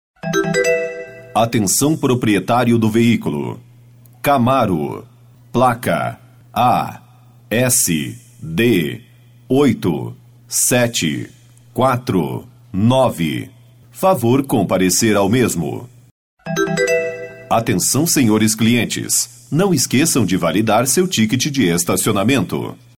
Com nosso sistema você terá mensagens pré-gravadas com a mesma qualidade e entonação de um locutor profissional, nada robotizado.
locutor-virtual-02.mp3